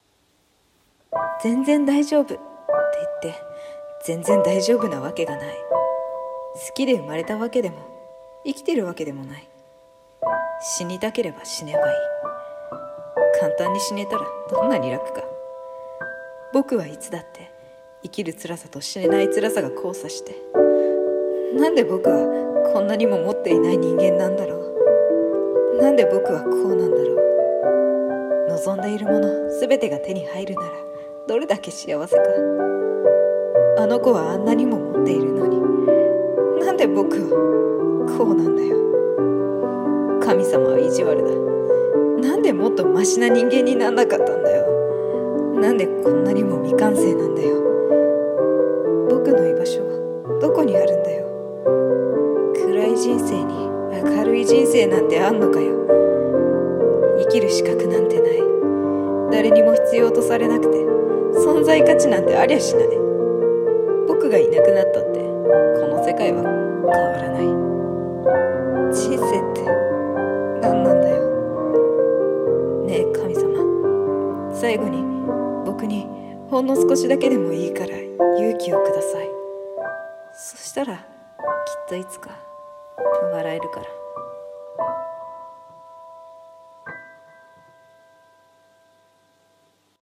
【声劇】勇気をください【一人声劇】